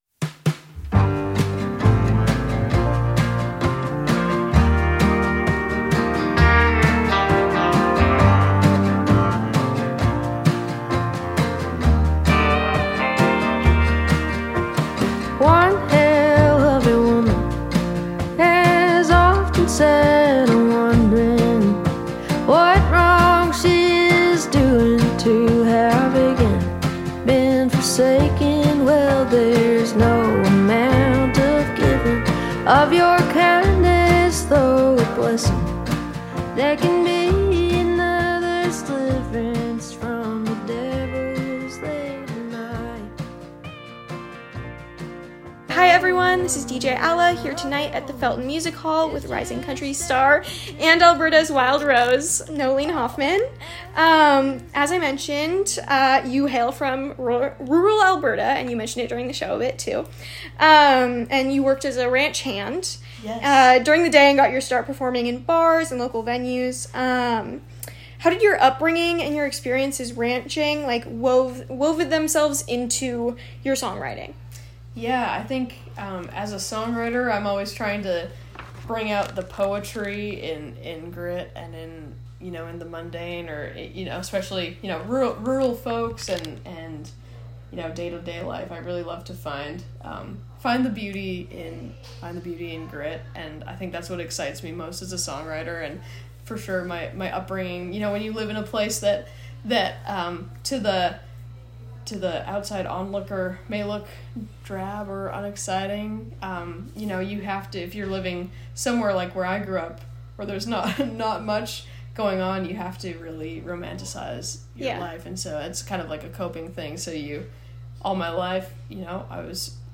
Interview Transcript:
KZSC Exclusive Interview.